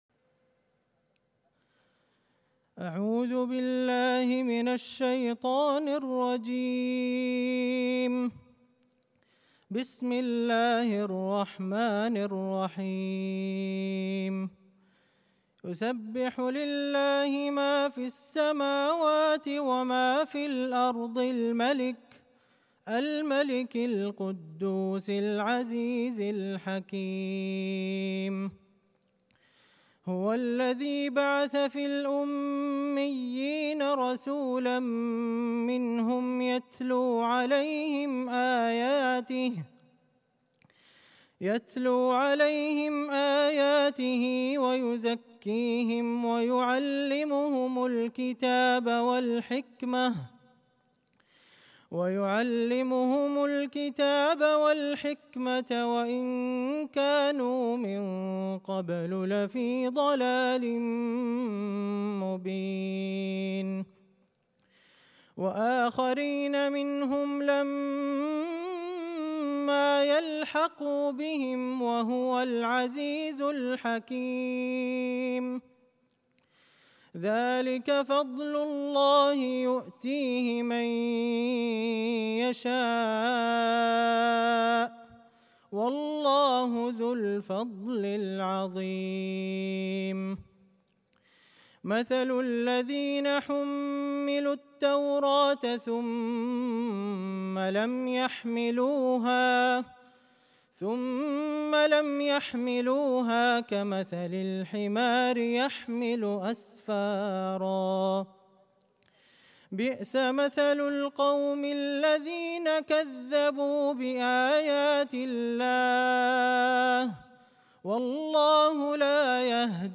تلاوة رائعة فذّة
في افتتاح معرض الكتاب 2022